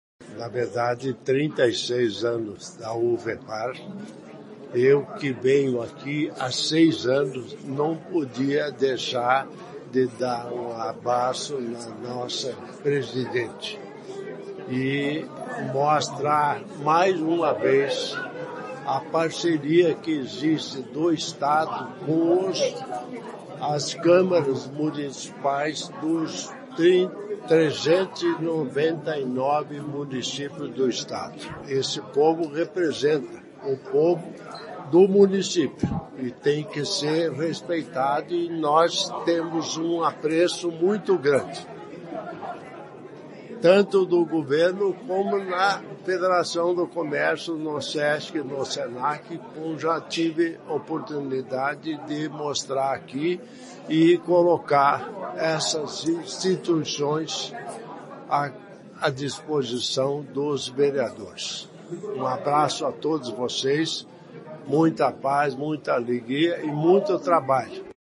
Sonora do governador em exercício, Darci Piana, sobre parceria com prefeituras e Legislativos
DARCI PIANA - EVENTO VEREADORES.mp3